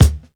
KICK_COLD_HEART.wav